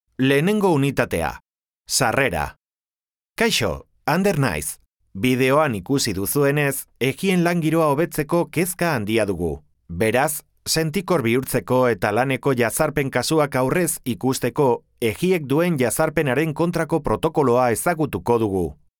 some of my jobs as online castilian spanish and basque voiceover